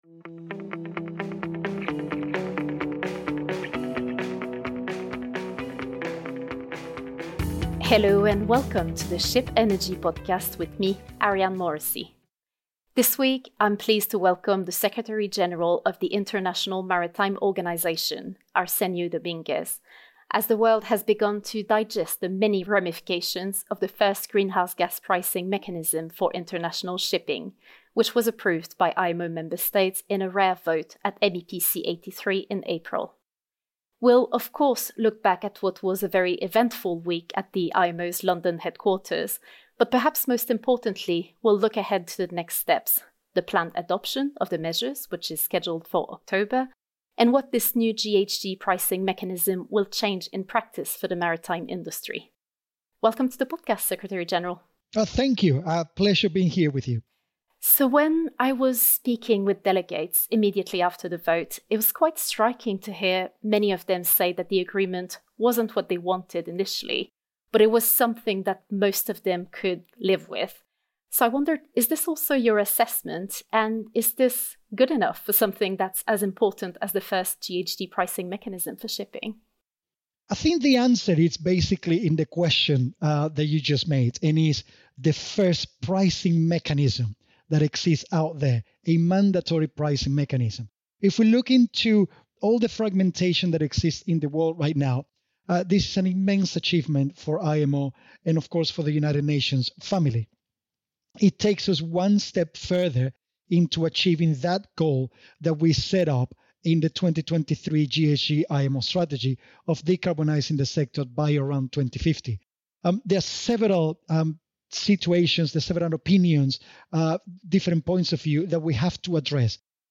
In this conversation, Arsenio Dominguez reflects on the eventful MEPC 83 meeting that led to IMO member states approving a ‘compromise’ text comprising mandatory requirements for the GHG intensity of the energy used by ships, with penalties for non-compliant vessels based on a tiered system.